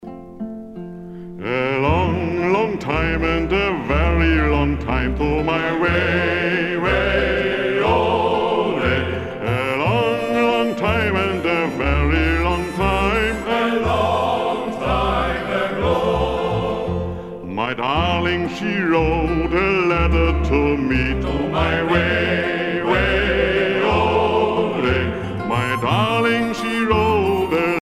circonstance : maritimes ;
Pièce musicale éditée